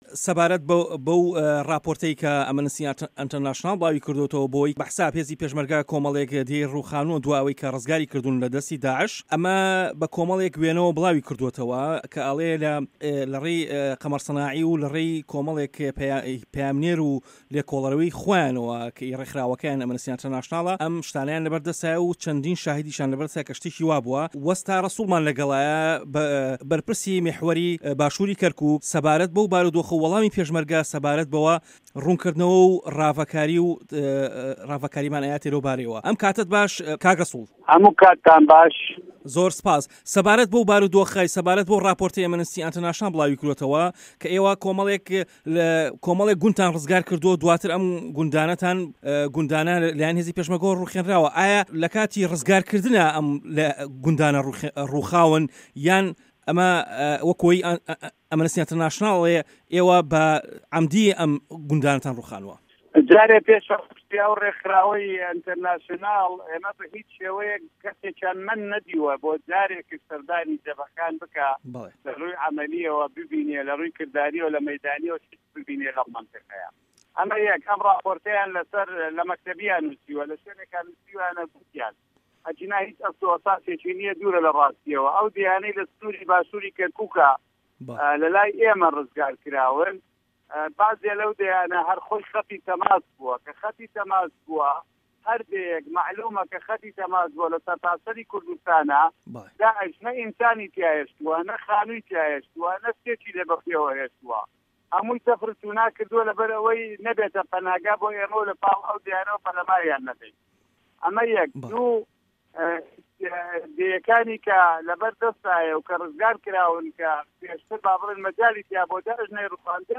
وتووێژ لەگەڵ جەنەڕاڵ ڕەسوڵ عومەر